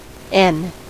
Uttal
Sökningen returnerar flera ord med olika skiftläge: n N ñ ℕ Uttal : IPA : /ˈɛn/ US: IPA : [ˈɛn] Ordet hittades på dessa språk: engelska Ingen översättning hittades i den valda målspråket.